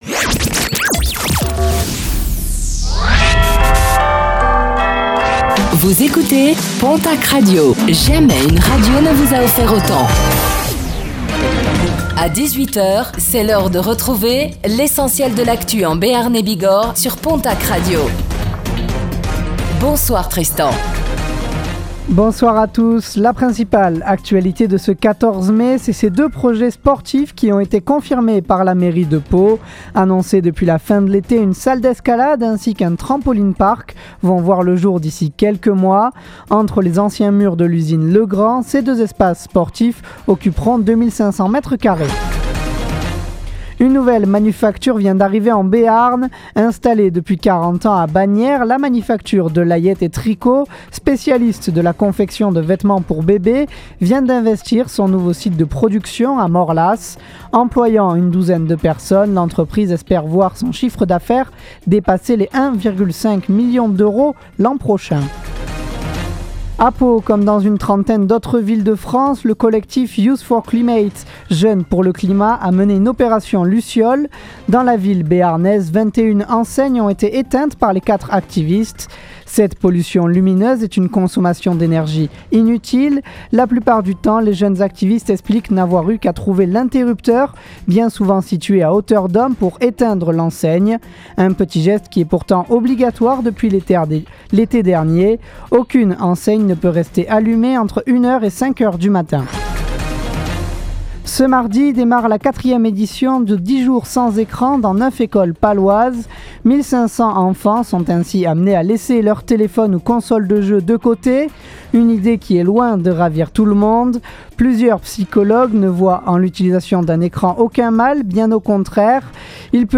Reportage : Conférence sur « comment bien vieillir » à Pontacq Interview